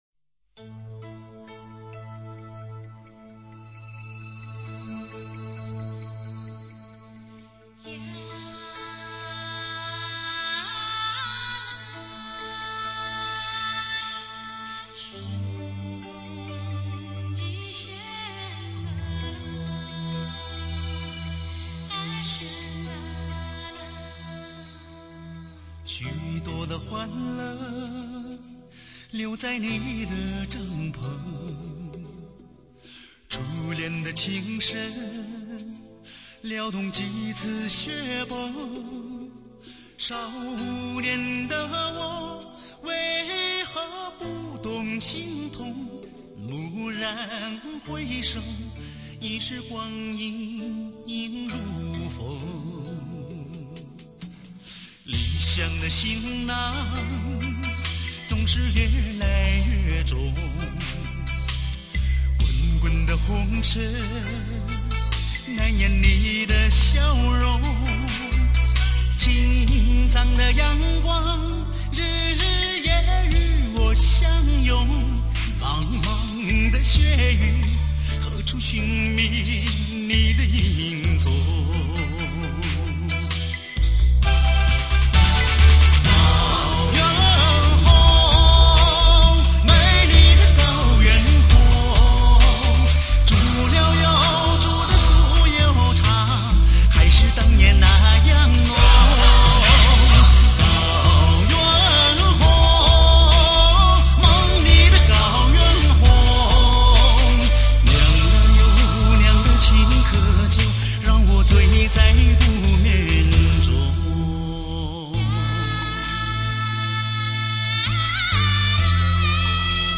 [mjh4][light]那歌声...那旋律...悠扬飘荡...[/light][/mjh4]